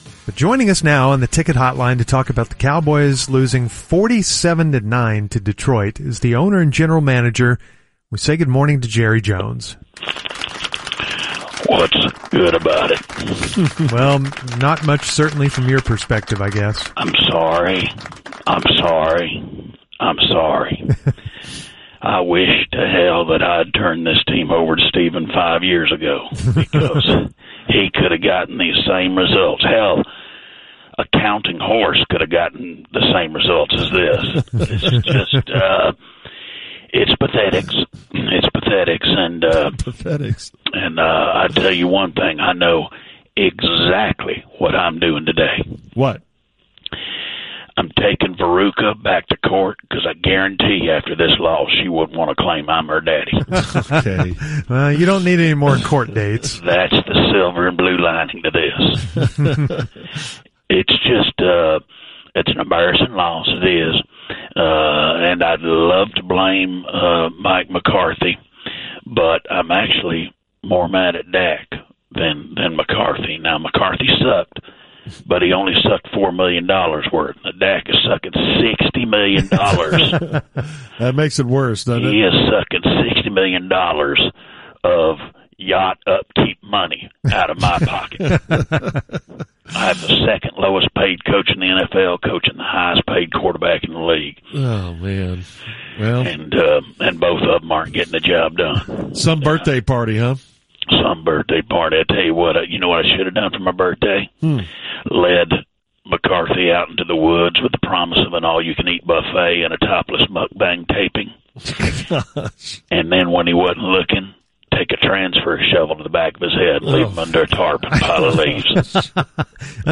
Fake Jerry Jones – The Musers 10.14.2024